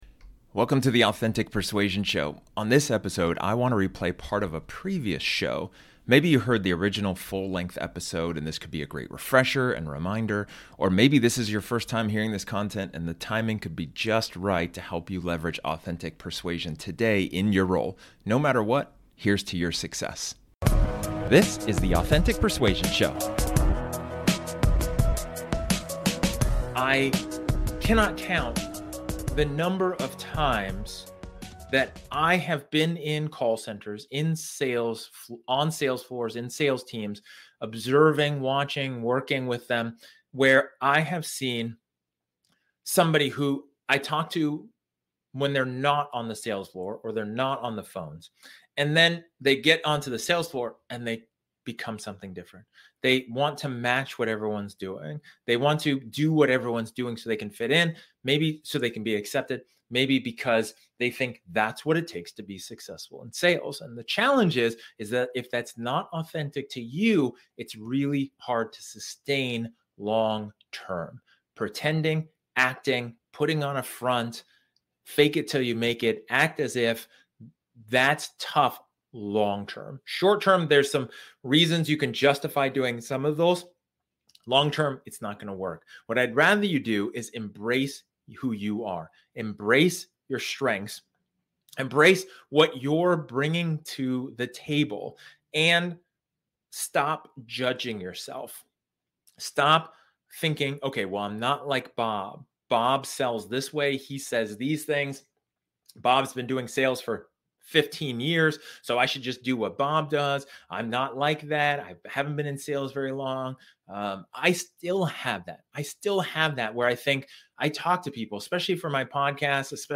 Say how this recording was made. In this episode, This episode is an excerpt from one of my training sessions where I talk about the importance of acknowledging your strengths.